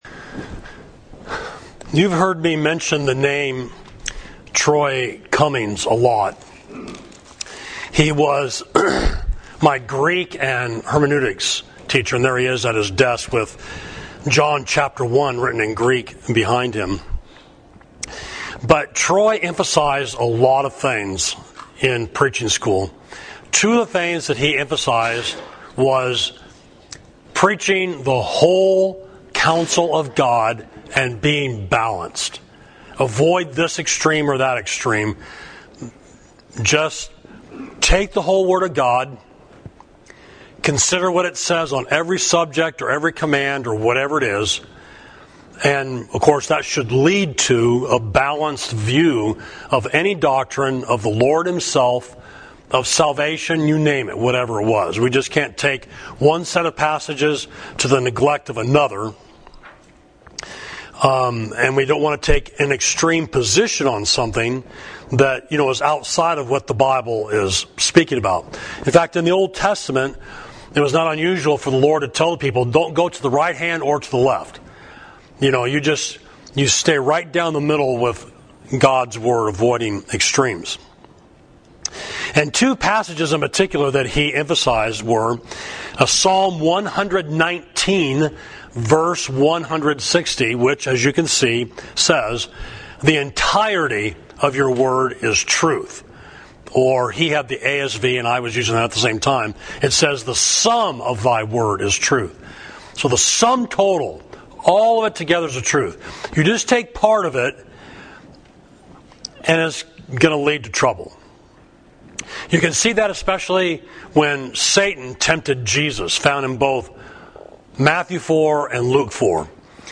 Sermon: Examining Calvinism – Total Depravity – Savage Street Church of Christ